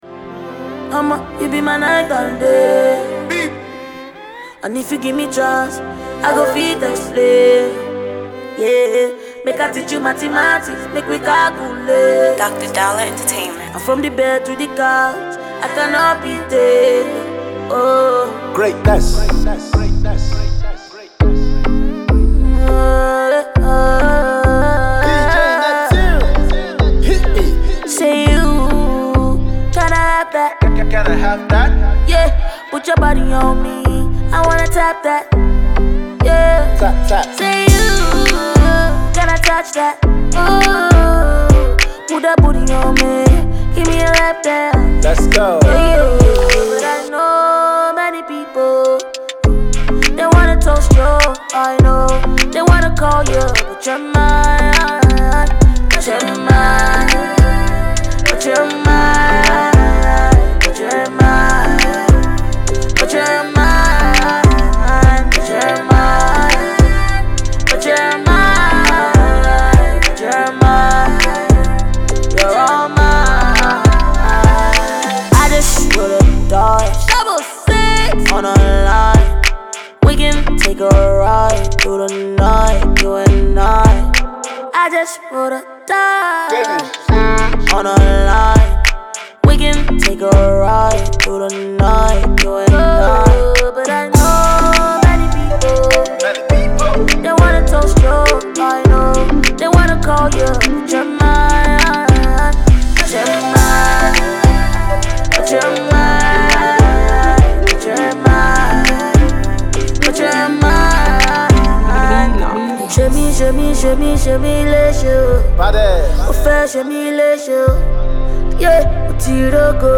pop
groovy and midtempo jam